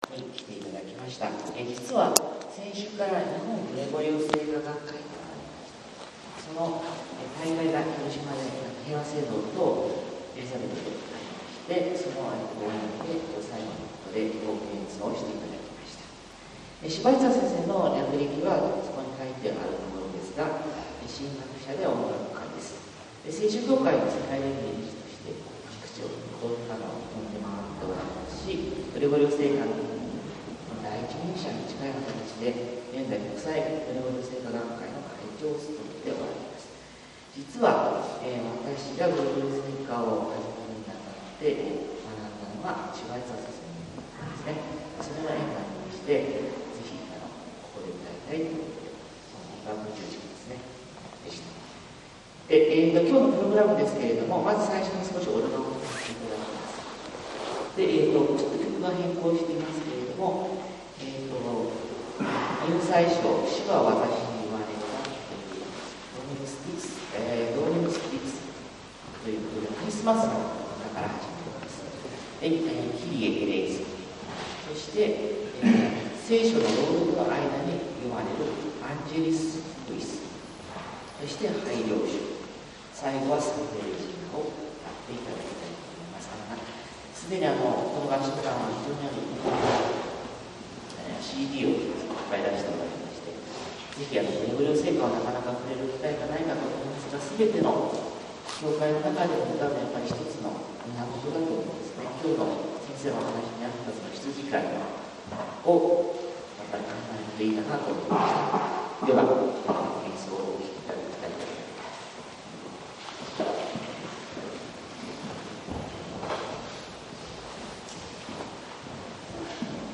コンソルティウム・ヴォカーレ オスロ・ミニコンサート